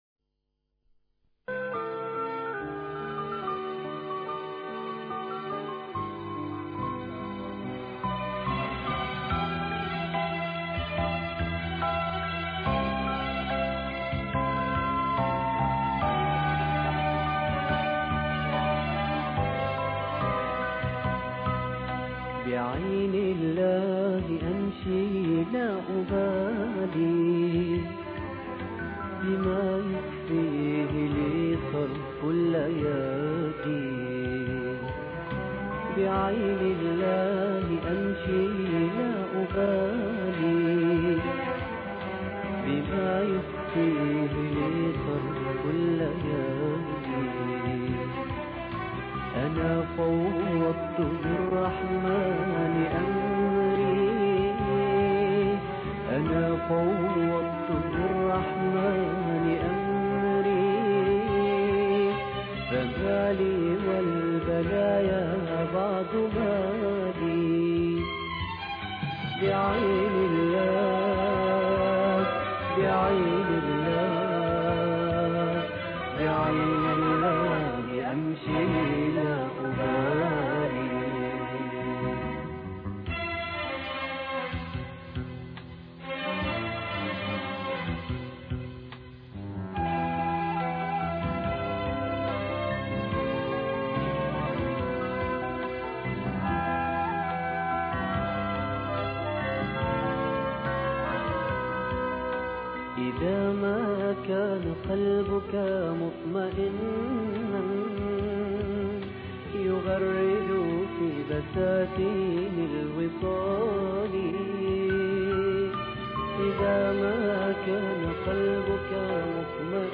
بعين الله امشي لا ابالي الخميس 23 إبريل 2009 - 00:00 بتوقيت طهران تنزيل الحماسية شاركوا هذا الخبر مع أصدقائكم ذات صلة الاقصى شد الرحلة أيها السائل عني من أنا..